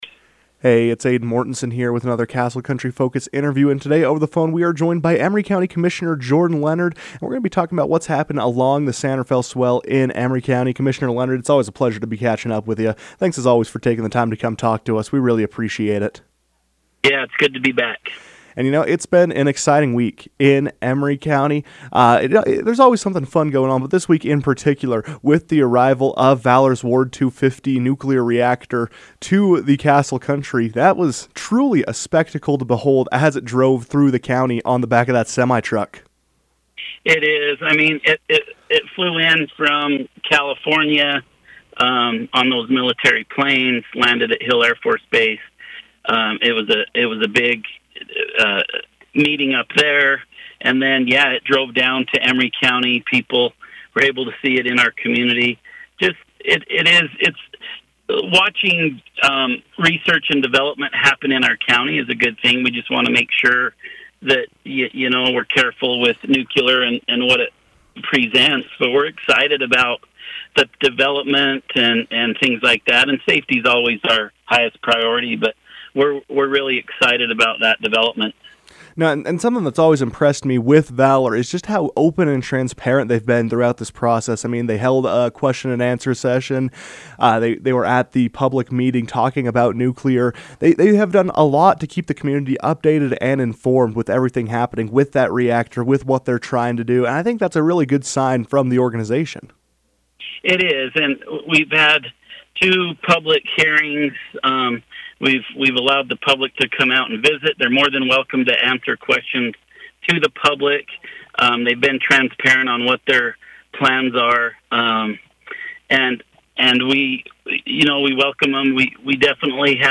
To discuss this balance, Commissioner Jordan Leonard joined the KOAL newsroom to share his perspective on what’s happening in the county.